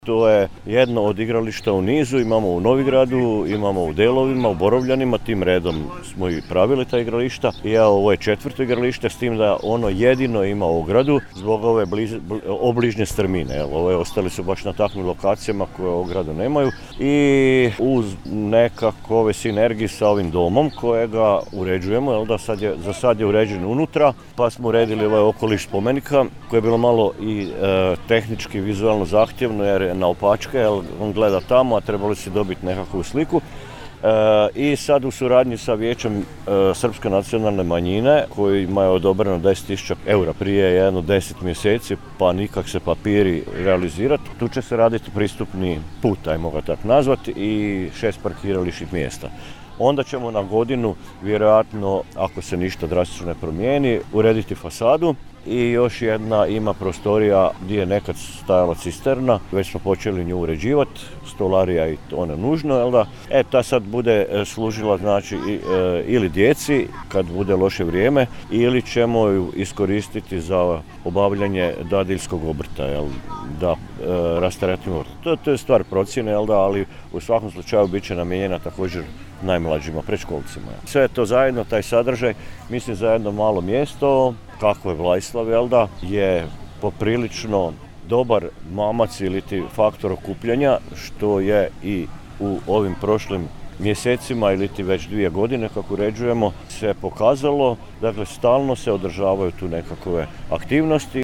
Kako je to prilikom otvorenja igrališta rekao načelnik Brljek, izgradnja je počela u ožujku i radovi su bili vrijedni nešto više od 40.000 eura, a iz nacionalnog fonda ostvareno je sufinanciranje od čak 37.000 eura;